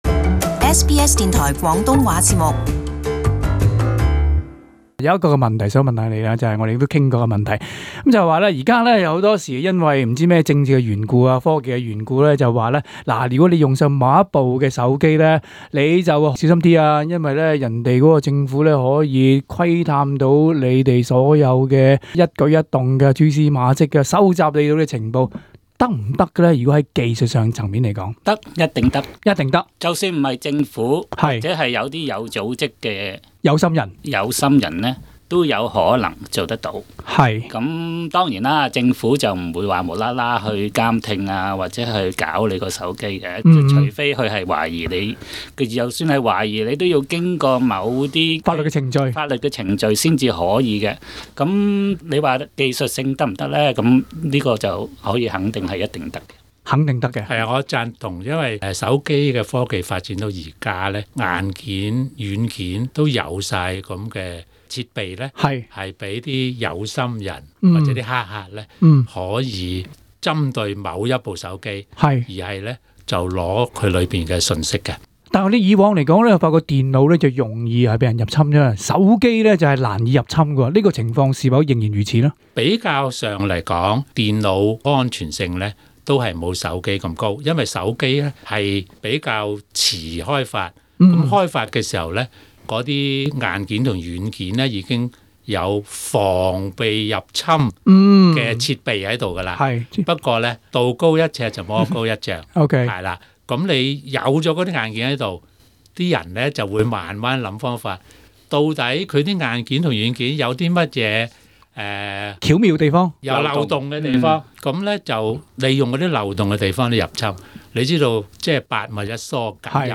【社區專訪】提防資訊被盜風險